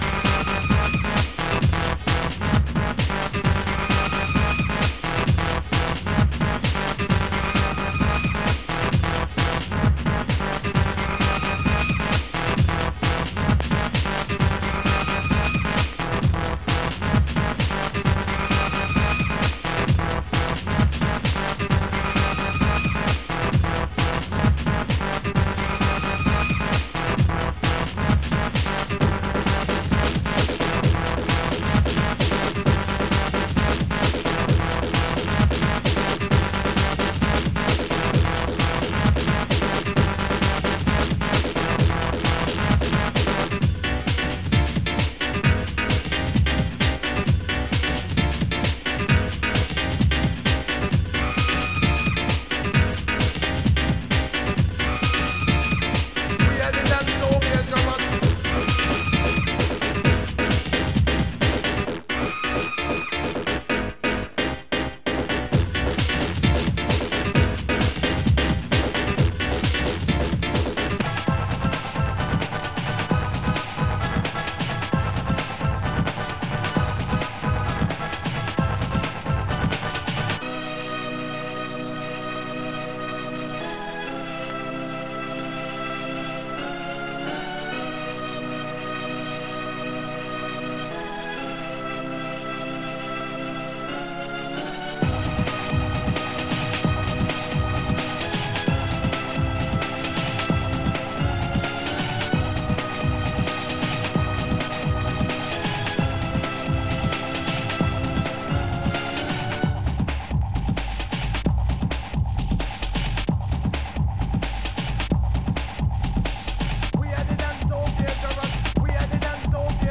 Hardcore , Breakbeat